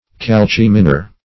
Calciminer \Cal"ci*mi`ner\